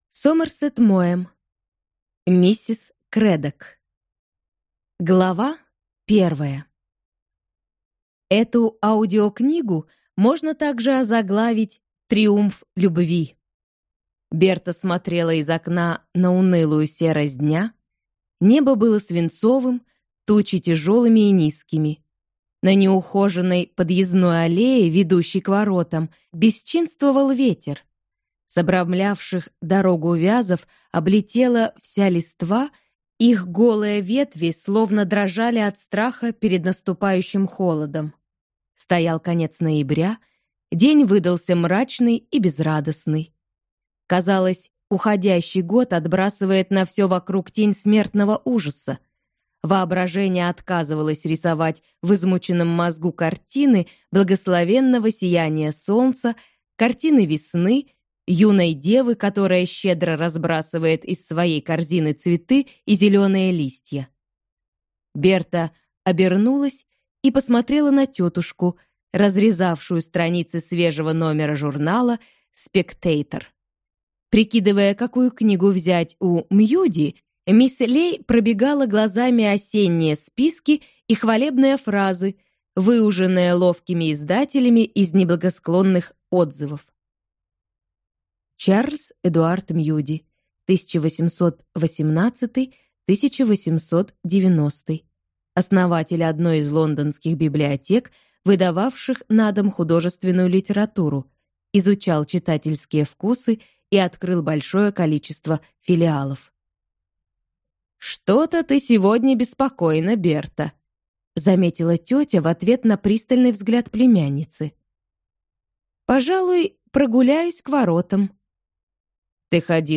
Аудиокнига Миссис Крэддок | Библиотека аудиокниг